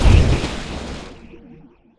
lifeimpact02.wav